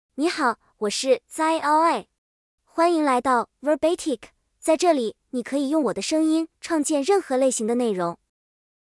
Xiaoyi — Female Chinese (Mandarin, Simplified) AI Voice | TTS, Voice Cloning & Video | Verbatik AI
Xiaoyi is a female AI voice for Chinese (Mandarin, Simplified).
Voice sample
Listen to Xiaoyi's female Chinese voice.
Xiaoyi delivers clear pronunciation with authentic Mandarin, Simplified Chinese intonation, making your content sound professionally produced.